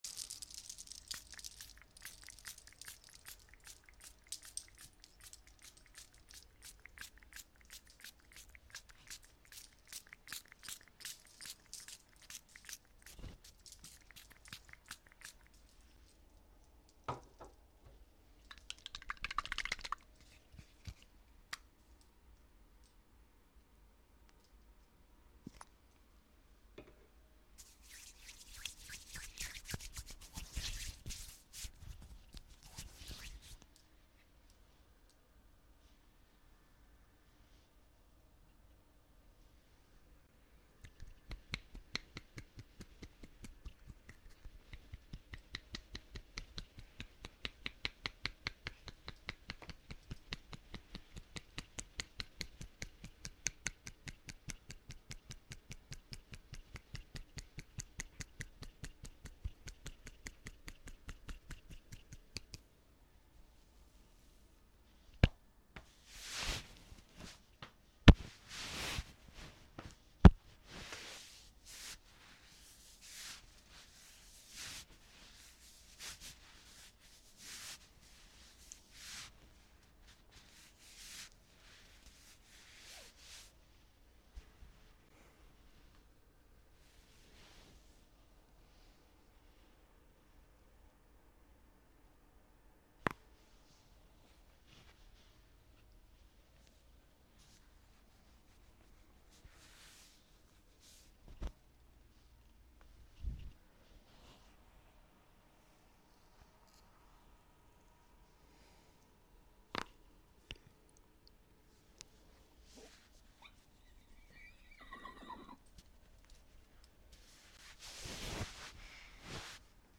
American Barber Gives $1 Relaxing